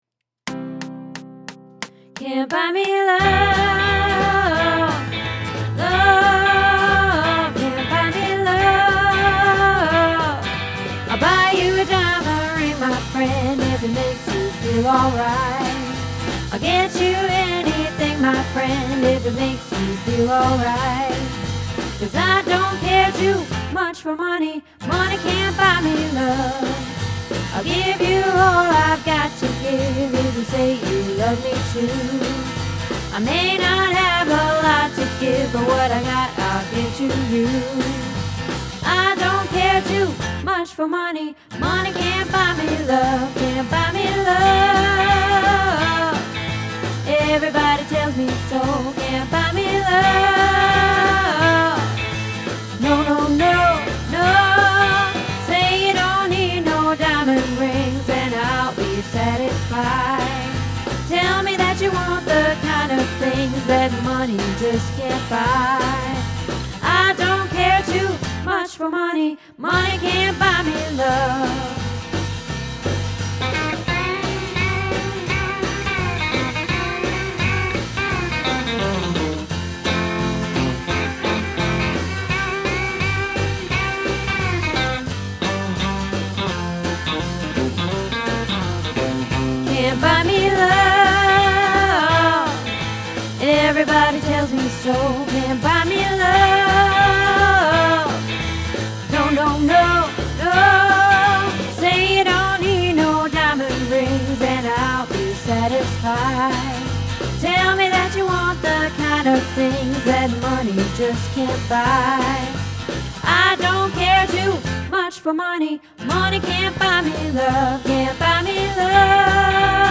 Can't Buy Me Love - Alto